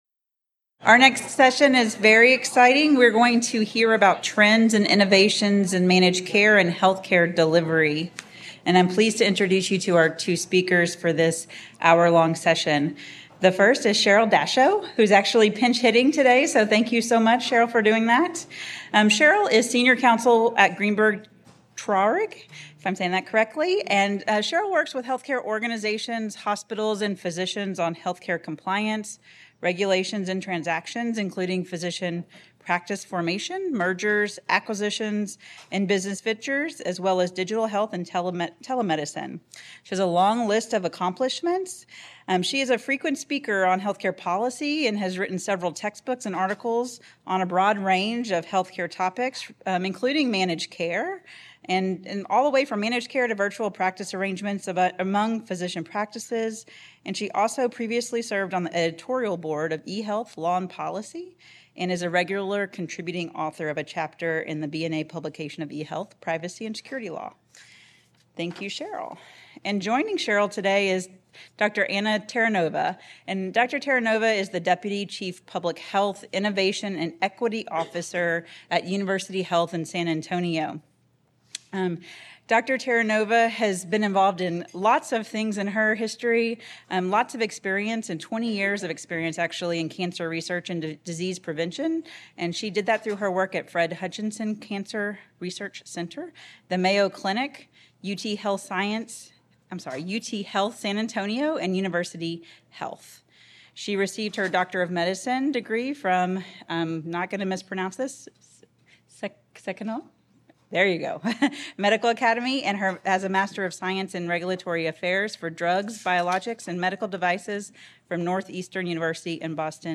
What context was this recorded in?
Originally presented: Apr 2024 Health Law Conference